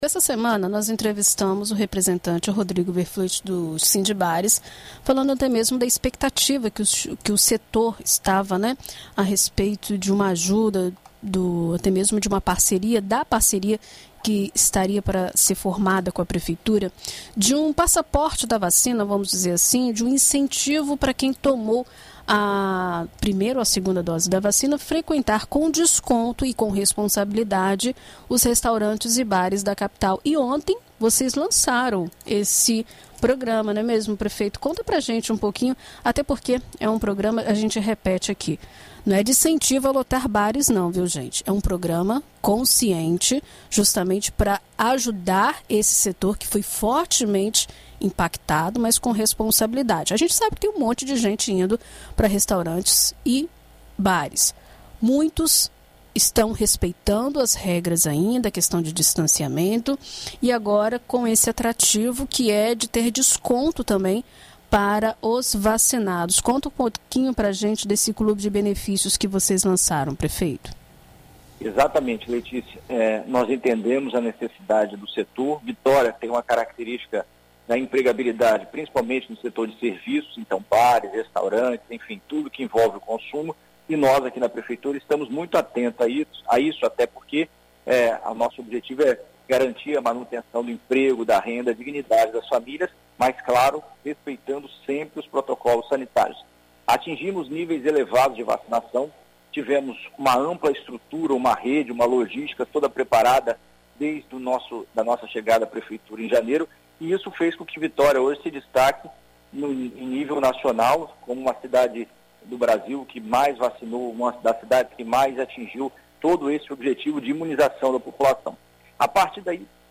Em entrevista à BandNews FM Espírito Santo nesta sexta-feira (24), o prefeito de Vitória, Lorenzo Pazolini, fala sobre a proposta e revela estar em conversa com o setor de turismo para a aplicação de um modelo similar, que deve trazer um estimulo à área.